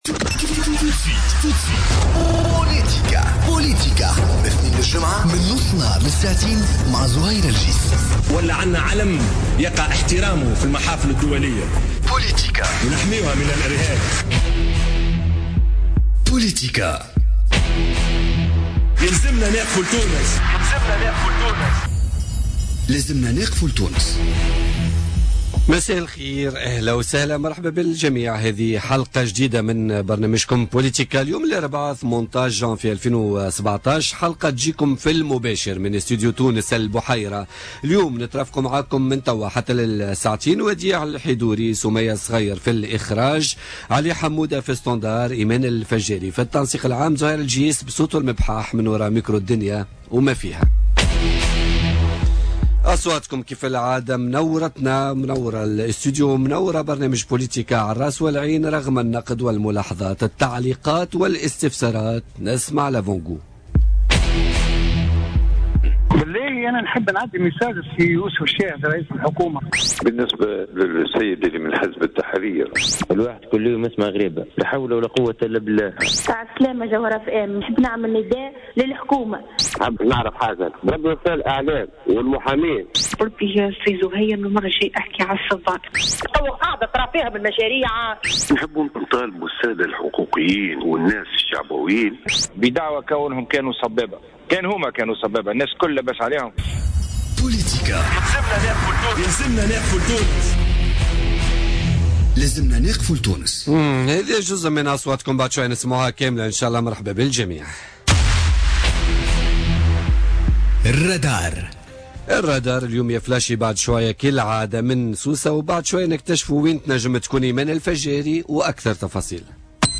Le député Fayçal Tebini, Mehdi Ben Gharbia ministre des Relations avec les Instances constitutionnelles, la Société civile et les Droits de l’Homme et Mongi Rahoui invités de Politica